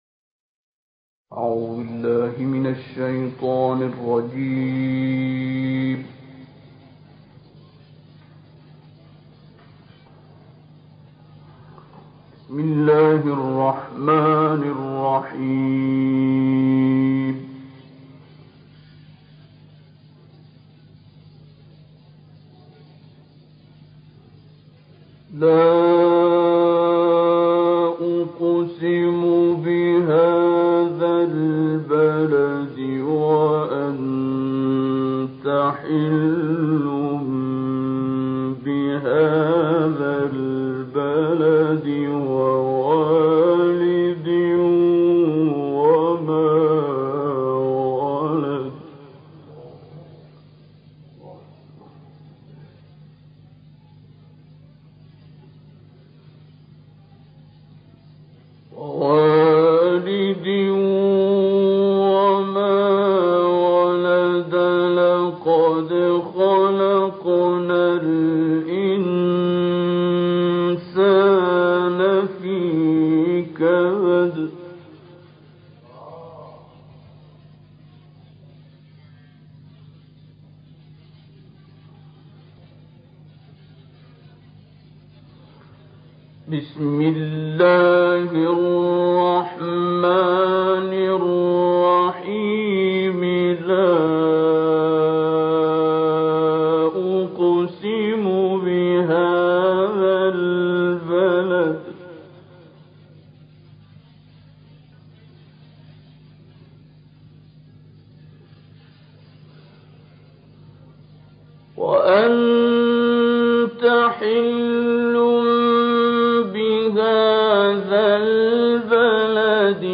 تلاوت سوره‌های "قصار" با صدای استاد عبدالباسط صوت - تسنیم
تلاوت سوره‌های بلد، شمس، ضحی، تین، علق و قدر با صدای استاد عبدالباسط محمد عبدالصمد منتشر شد.